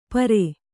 ♪ pare